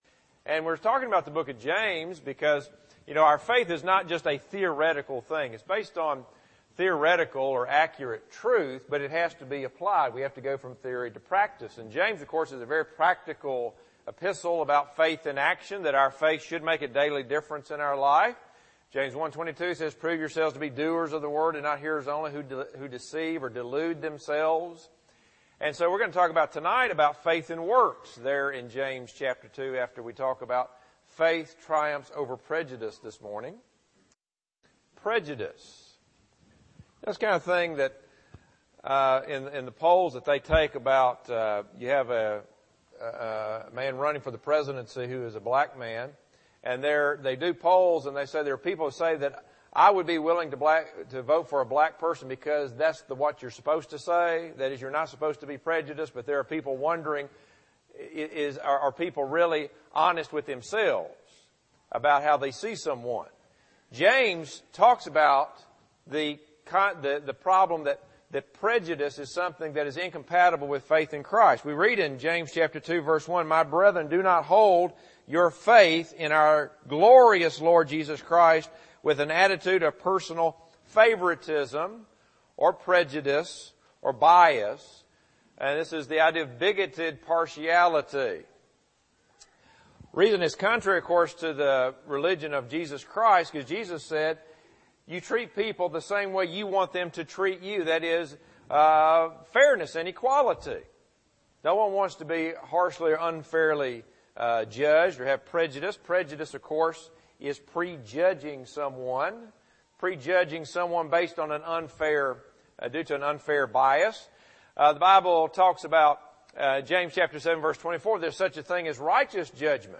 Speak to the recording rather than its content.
Series: Book of James Service: Sun AM Type: Sermon